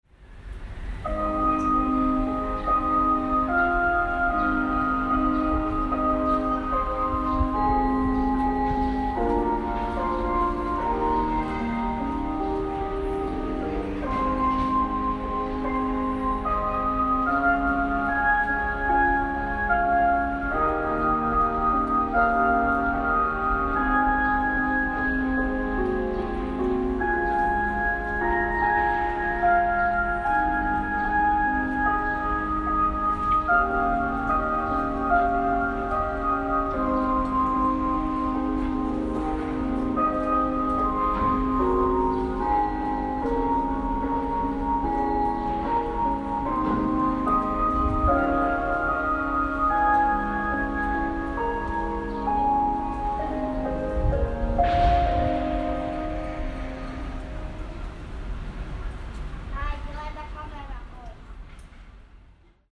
Kaiuttimet koulun katolla Shinjugun alueella.